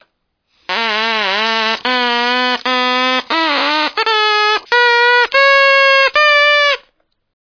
Hoe kleiner het rietje hoe hoger de toon.